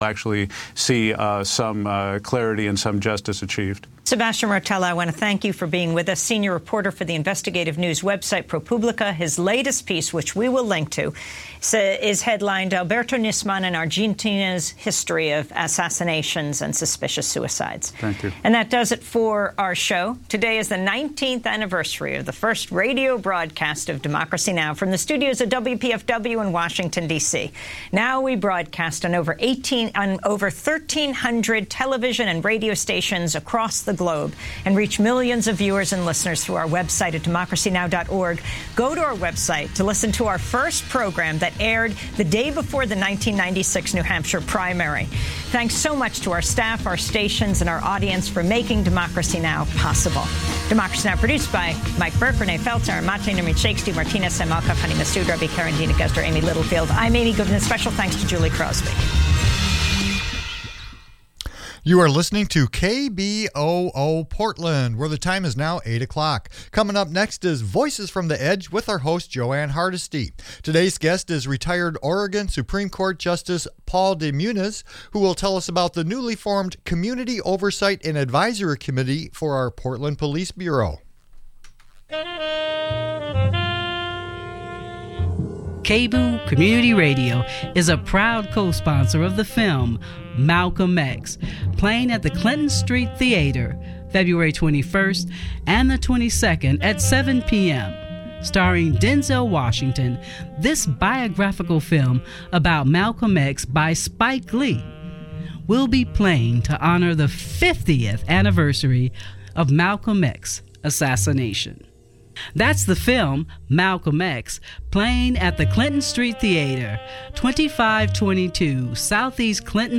Progressive talk radio from a grassroots perspective